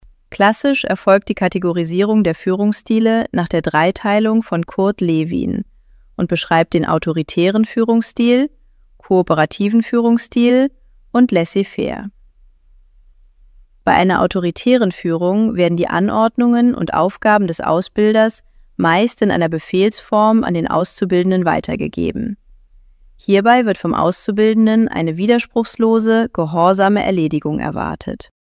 Folge 5 der Podcast-Reihe „Lektion k„, gesprochen vom virtuellen Avatar Rebecca Marenfeldt.